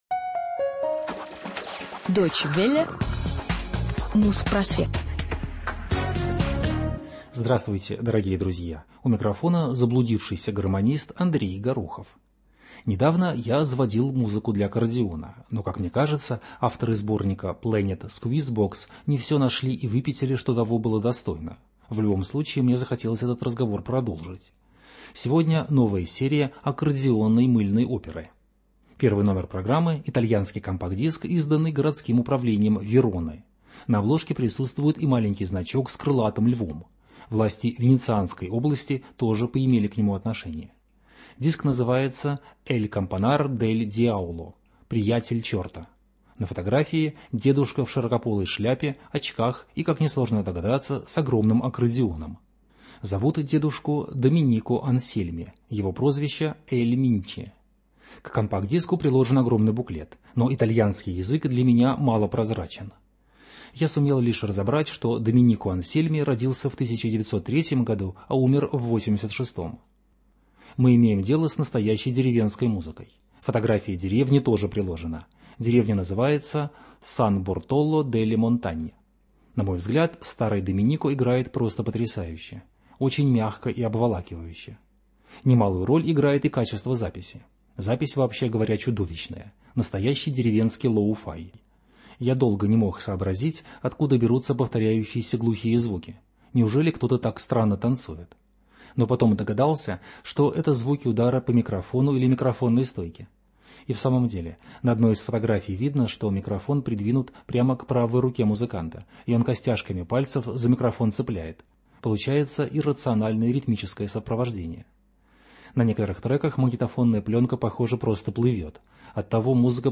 Музпросвет 301 от 17 мая 2008 года - Музыка для аккордеона 3 | Радиоархив